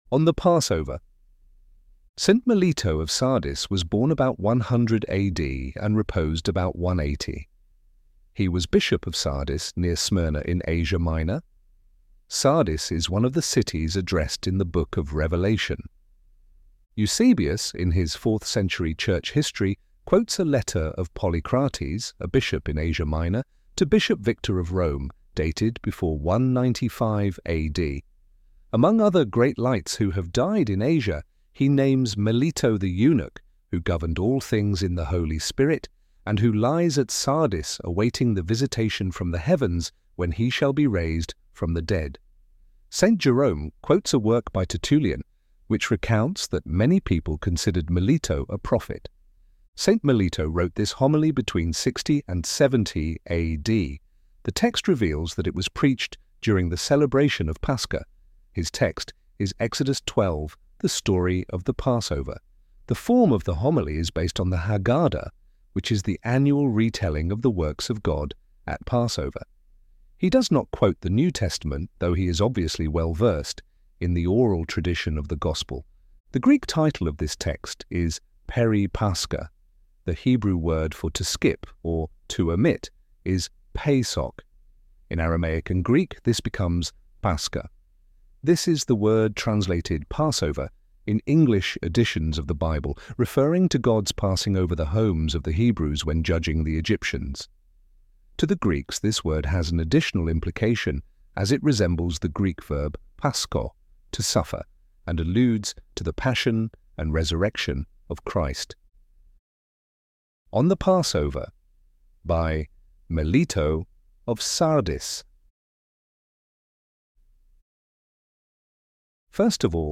A Paschal sermon by Saint Melito, bishop of Sardis (+180AD)